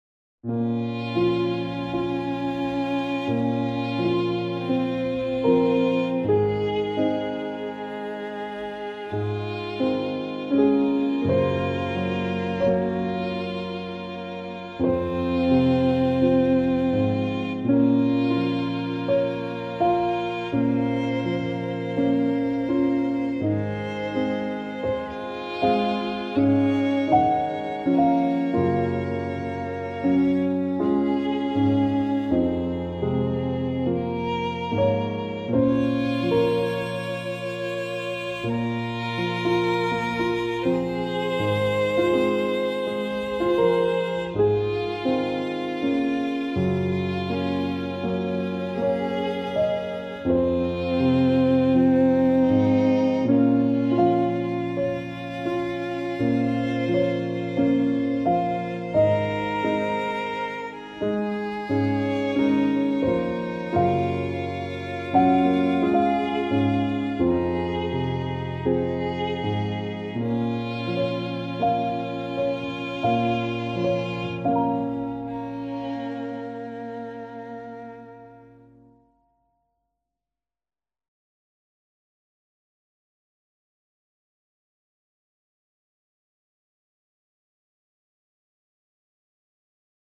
piano - triste - romantique - melancolique - tristesse